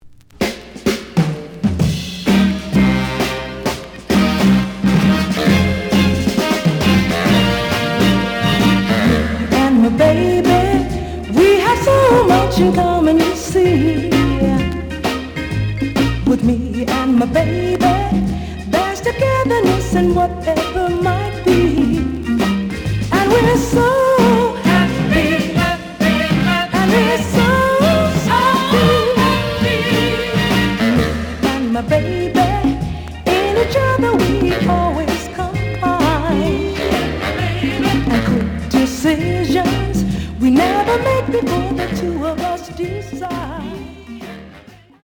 The audio sample is recorded from the actual item.
●Genre: Soul, 60's Soul
B side plays good.)